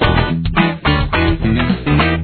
Main Riff
like together (with bass):